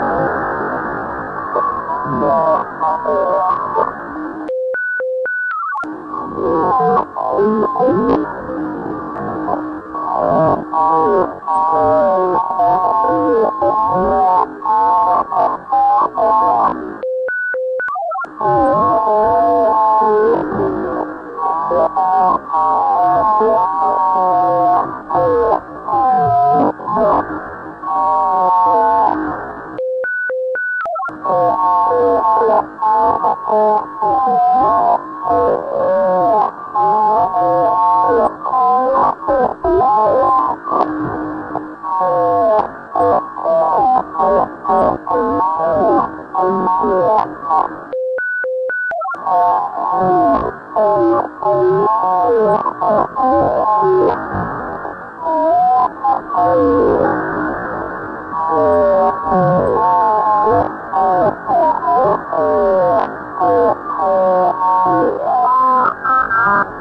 描述：从基站到外星飞船的信息。
Tag: 外星人 aliencommunication alienspeech 消息 无线